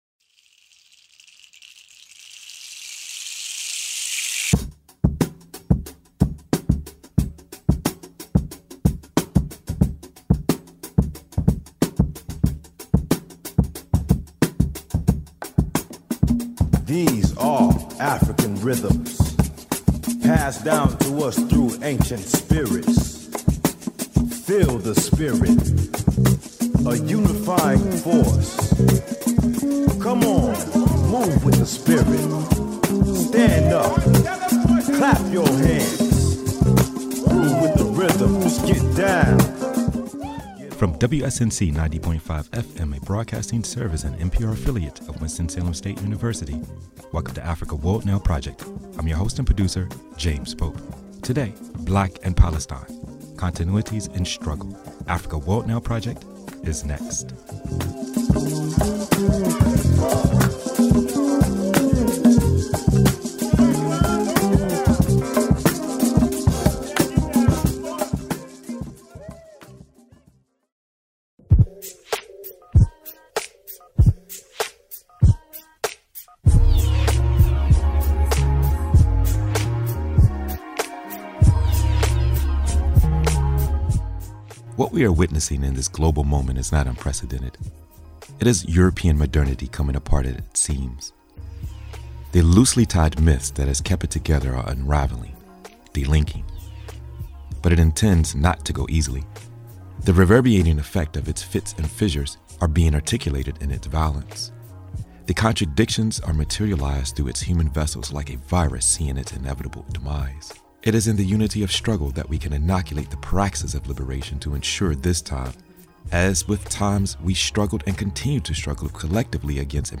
through interviews with and presentations from artists, activists, scholars, thinkers, practitioners, and other stake holders throughout the Africana world.